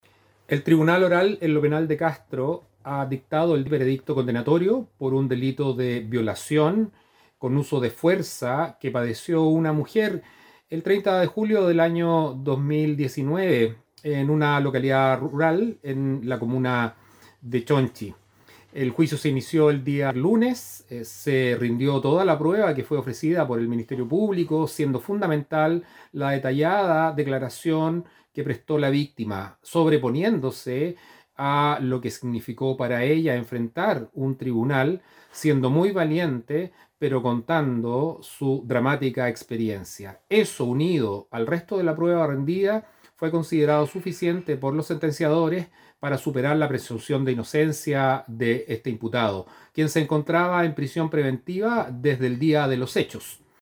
El fiscal jefe de Castro del Ministerio Público, Enrique Canales, se refirió a la resolución del tribunal, manifestando su conformidad por la decisión.